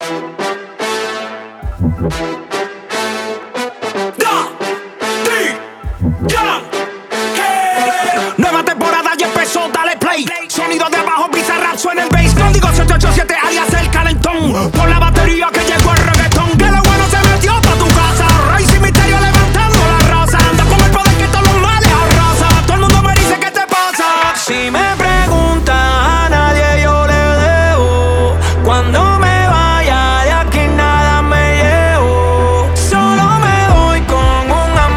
Urbano latino
Жанр: Латино